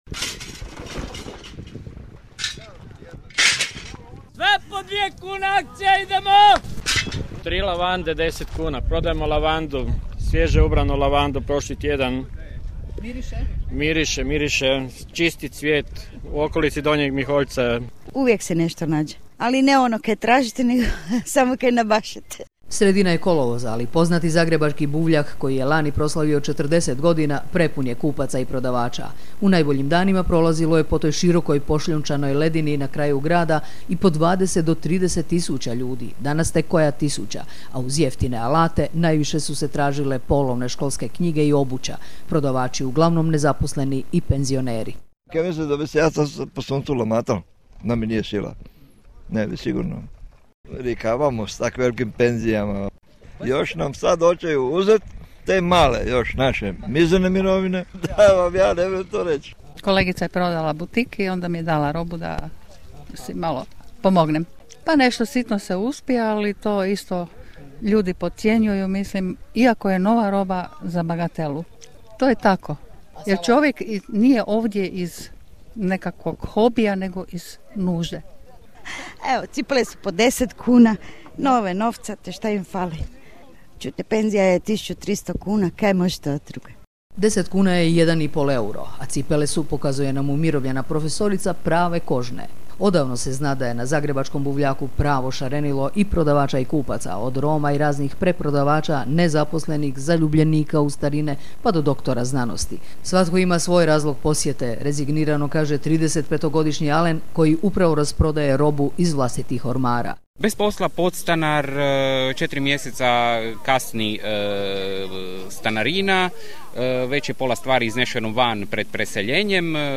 Reportaža sa zagrebačkog buvljaka Hrelić